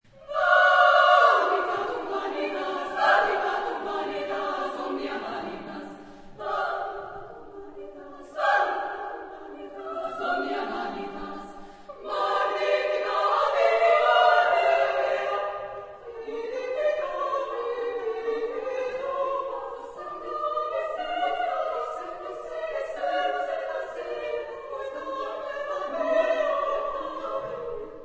Género/Estilo/Forma: Obra coral ; Sagrado
Tipo de formación coral: SSA  (3 voces Coro femenino )
Tonalidad : libre